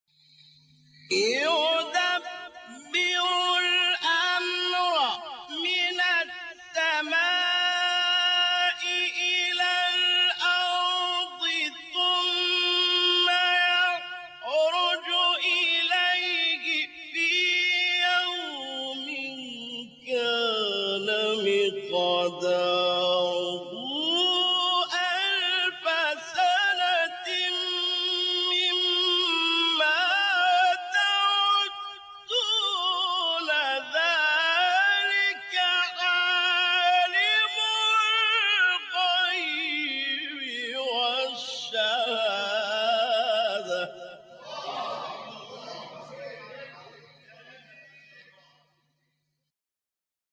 گروه شبکه اجتماعی: مقاطعی از قاریان مصری که در مقام رست اجرا شده است، می‌شنوید.
مقام رست